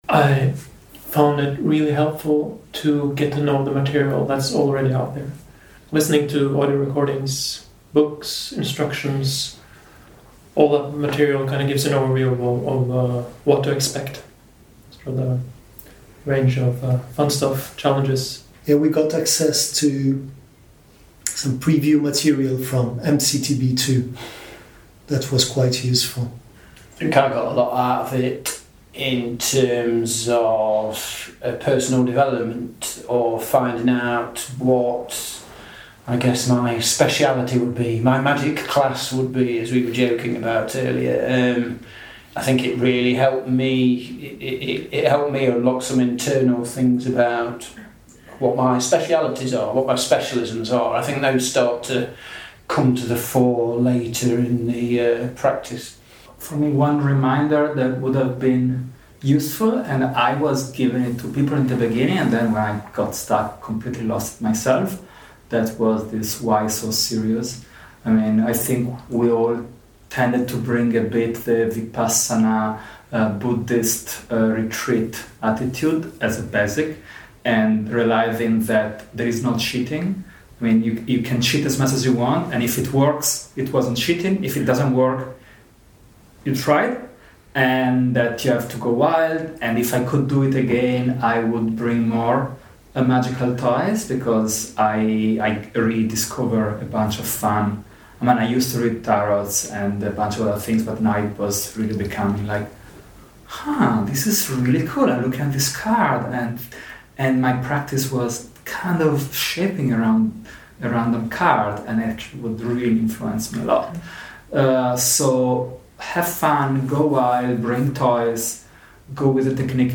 All nine meditators
got together at the end of the 2018 France fire kasina retreat and gave advice that they though would be helpful for others who might do this style of practice, as well as reports of their experiences and perspectives on what happened.